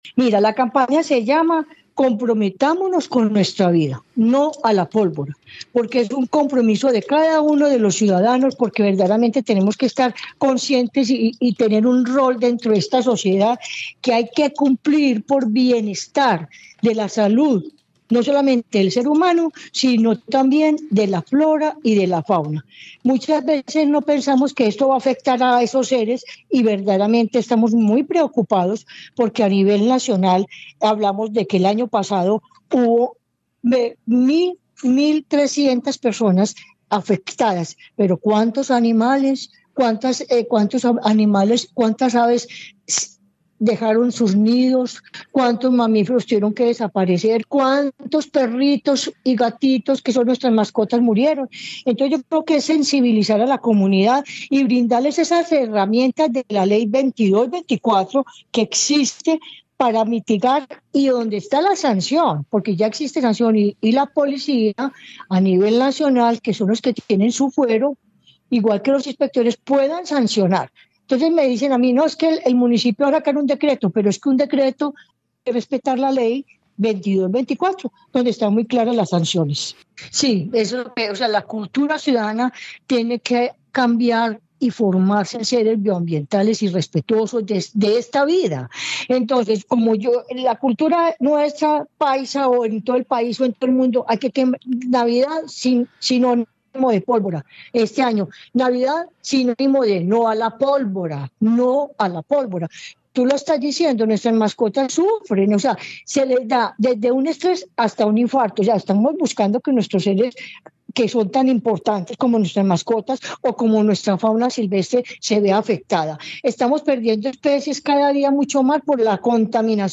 En diálogo con Caracol Radio